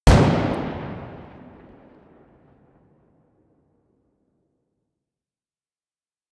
fire_dist.ogg